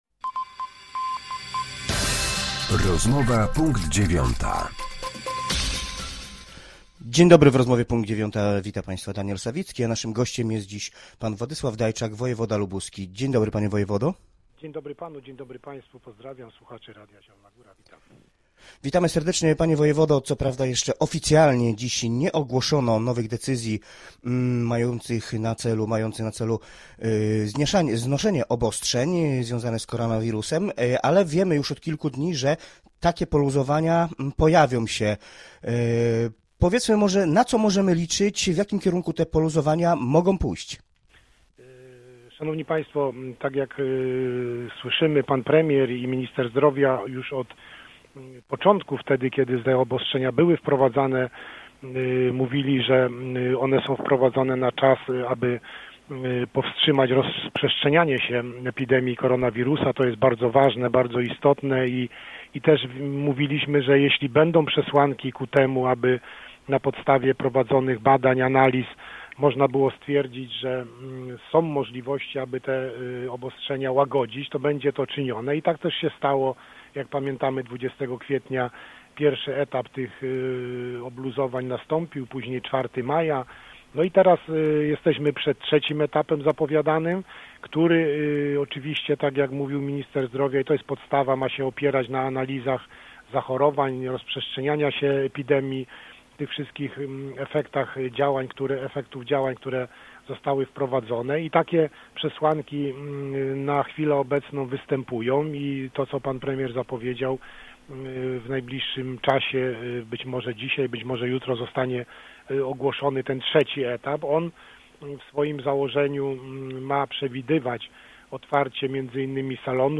Z wojewodą lubuskim rozmawia